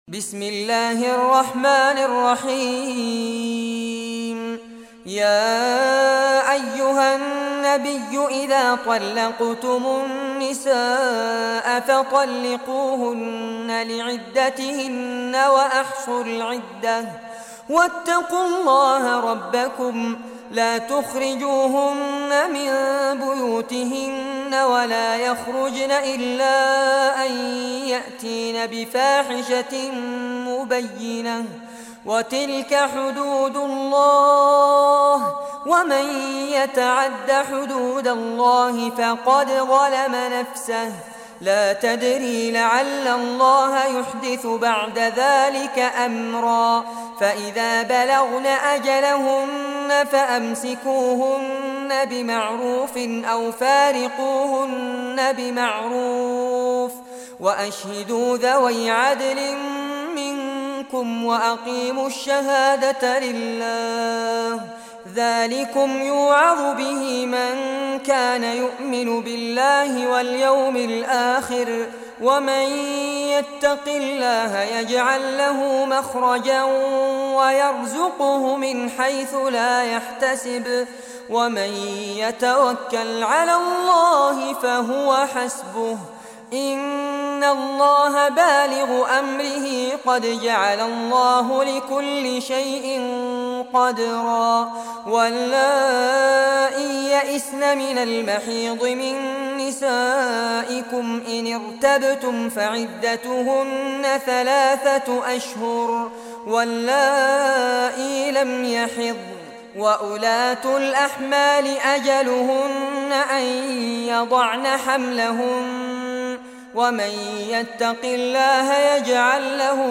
Surah At-Talaq Recitation by Fares Abbad
Surah At-Talaq, listen or play online mp3 tilawat / recitation in Arabic in the beautiful voice of Sheikh Fares Abbad.